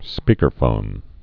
(spēkər-fōn)